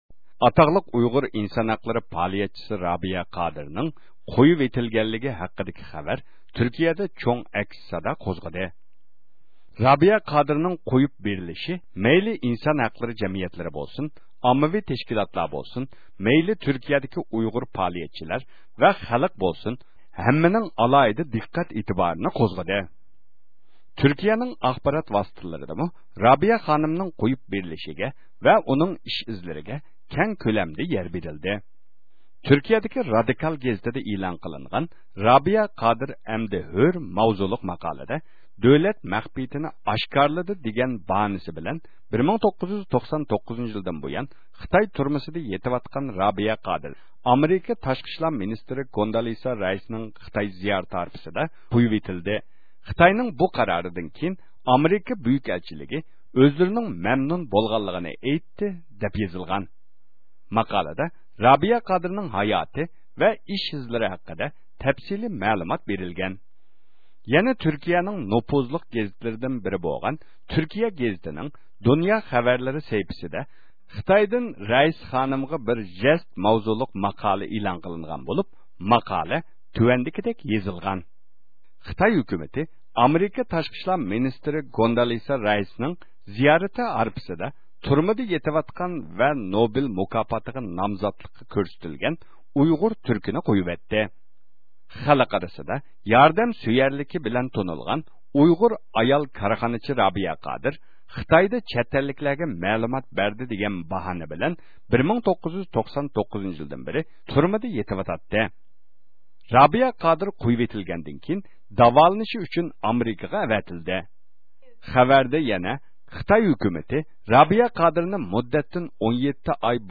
رابىيە قادىرنىڭ، جۈملىدىن ئۇيغۇرلارنىڭ كىشىلىك ھوقۇقى ئۈچۈن كۈچ چىقىرىۋاتقان تۈركىيىدىكى تەشكىلات رەخبەرلىرى زىيارىتىمىزنى قوبۇل قىلىپ، ئۆزلىرىنىڭ رابىيە قادىر خانىمنىڭ قويۇب بېرىلىشىگە بولغان خۇشاللىقلىرىنى ئىپادىلەشتى.